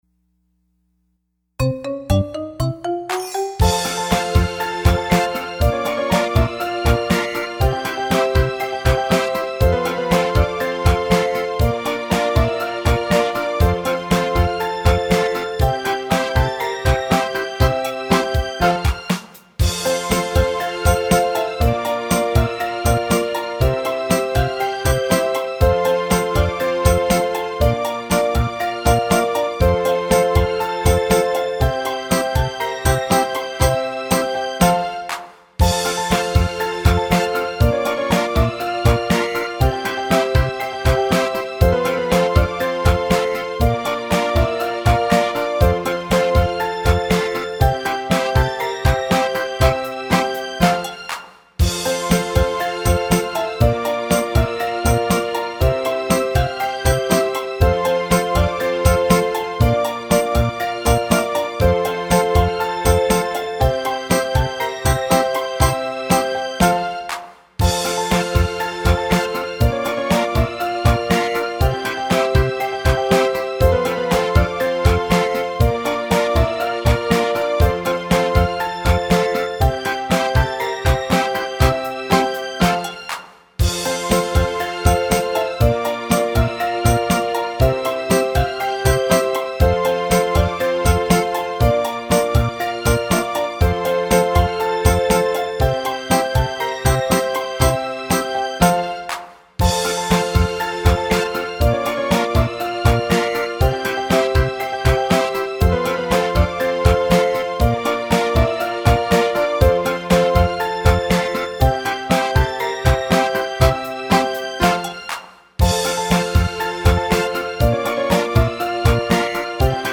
カラオケ体操バージョンもあるよ！
（歌声無し）